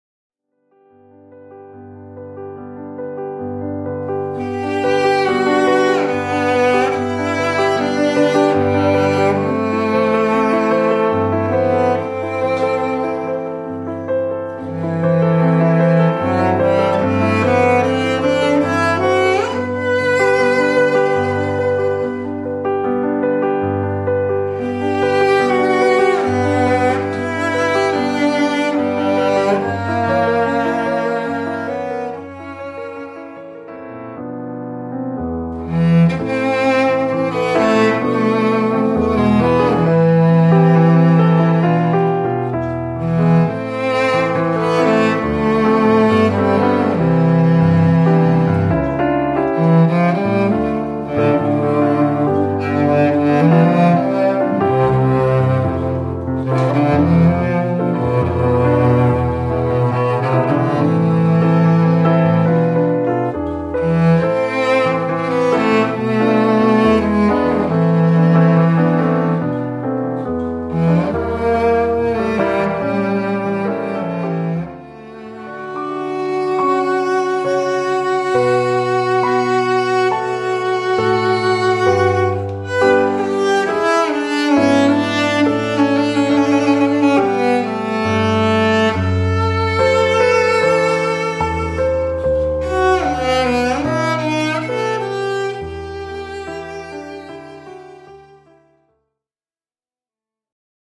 cello and piano duo